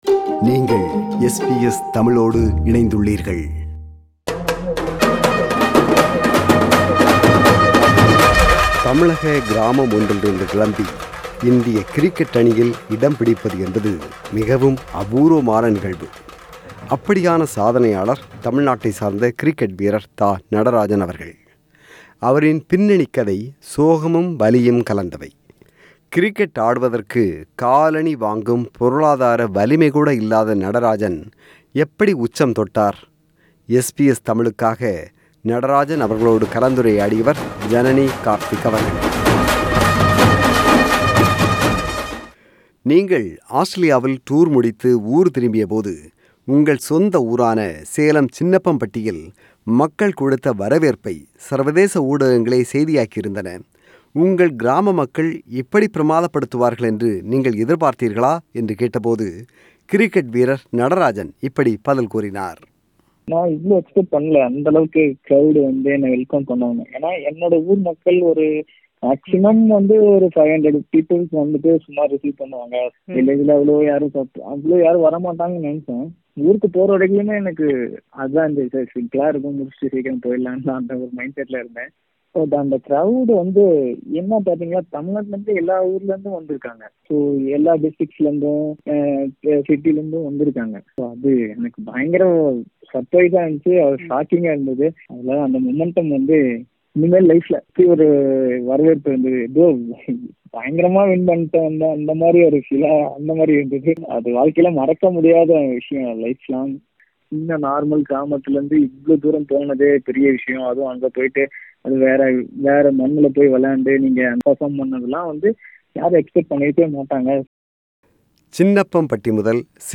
கிரிக்கெட் ஆடுவதற்கு காலணி வாங்கும் பொருளாதார வலிமைகூட இல்லாத நடராஜன் எப்படி உச்சம் தொட்டார்? SBS தமிழுக்காக நடராஜன் அவர்களோடு கலந்துரையாடியவர்